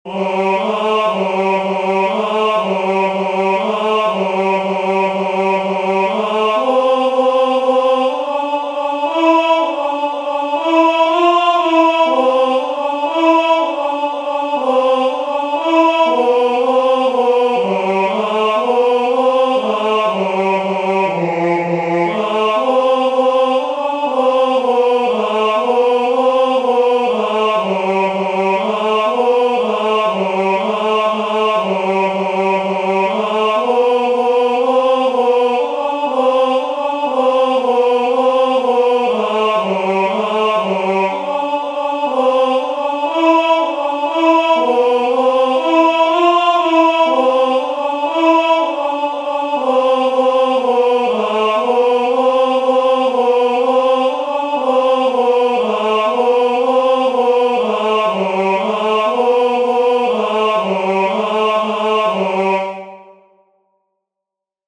"Ecce ego mitto," the first responsory from the first nocturn of Matins, Common of Apostles